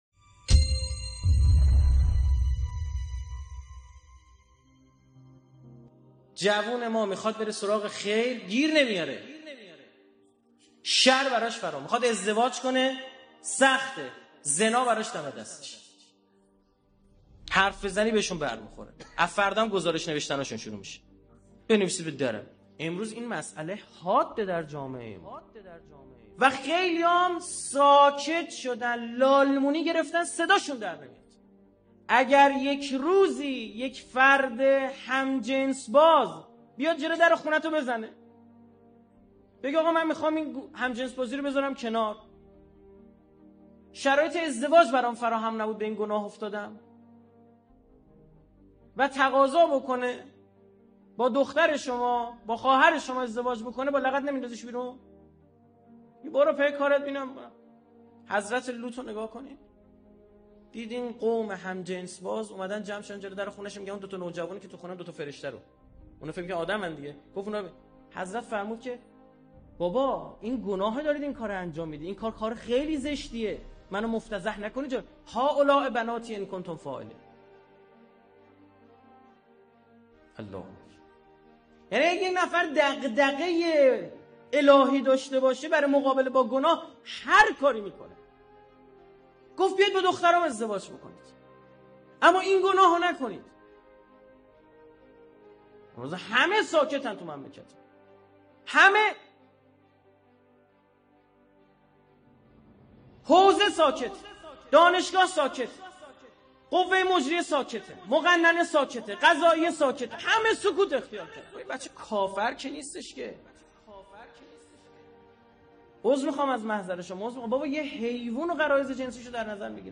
1 آخرین مطالب موسیقی استاد علی اکبر رائفی پور سخنرانی سخنرانی